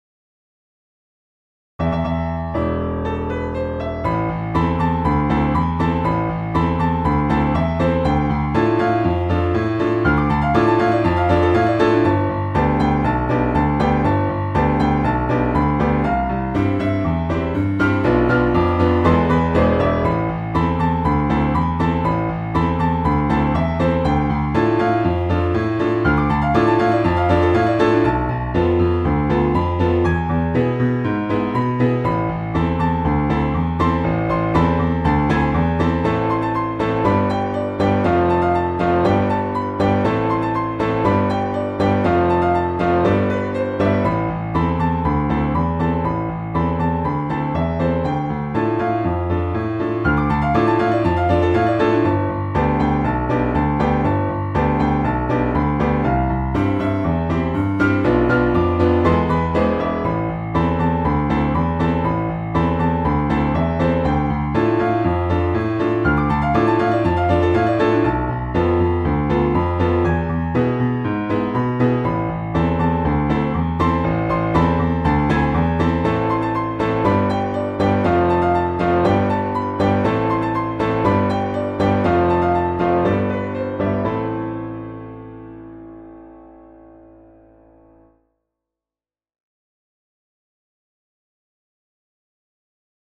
A scaled-down piano duet arrangement
Game Music